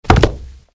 Toon_bodyfall_synergy.ogg